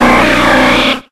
infinitefusion-e18 / Audio / SE / Cries / DEWGONG.ogg